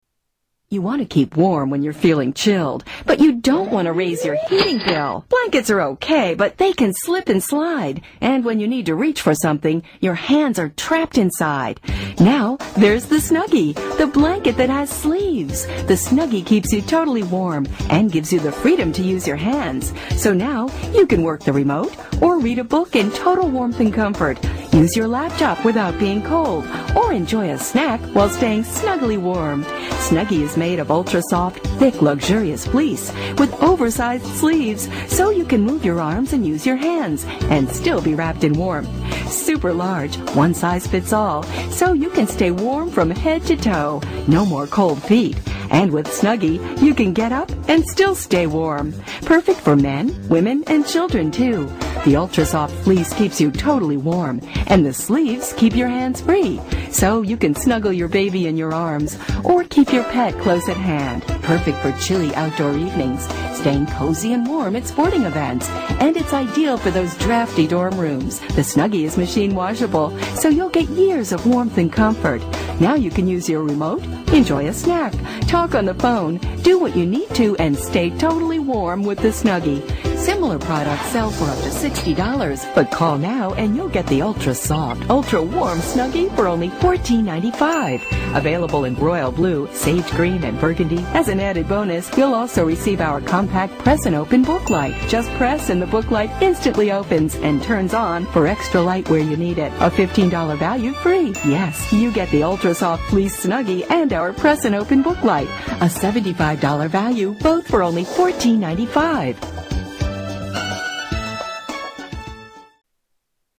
Original commerical